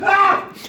Sound Buttons: Sound Buttons View : Eddy Fear Scream V1
eddy-yell_UG9Xh1U.mp3